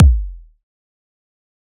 EDM Kick 36.wav